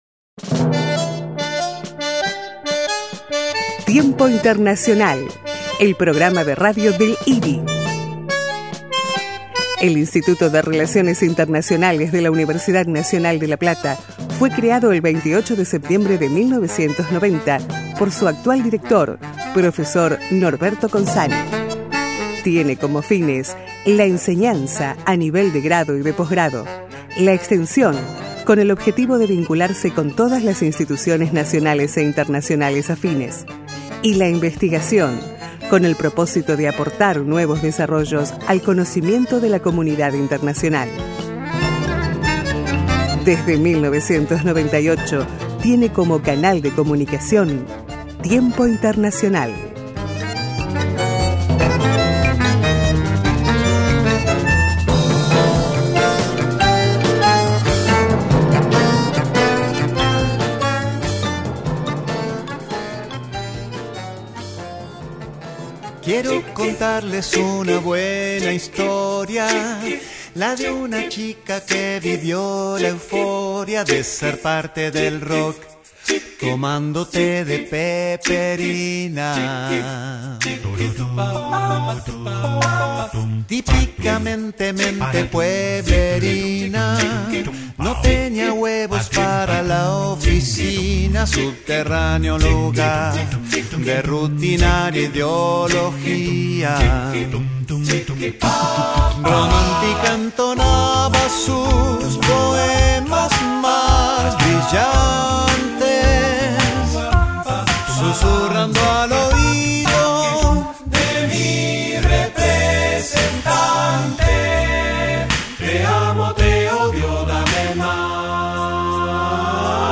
Cooperación Internacional; Agenda 2030. Entrevista